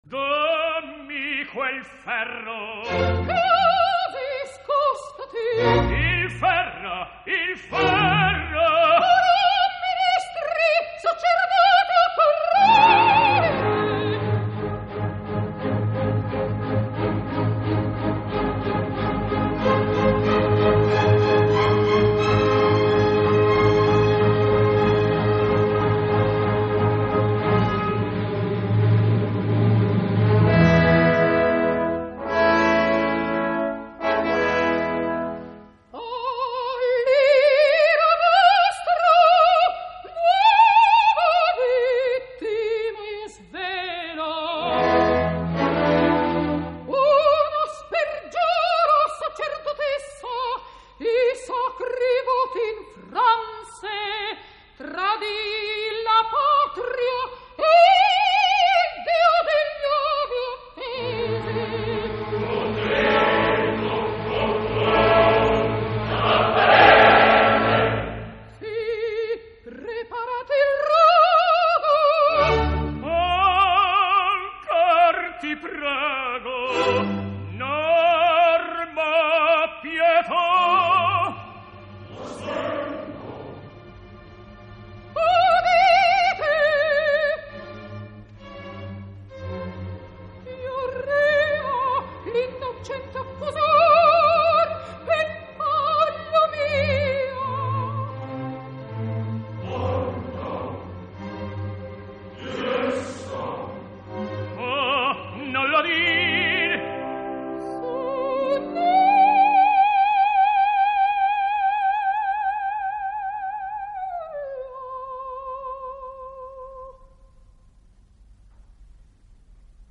Official recording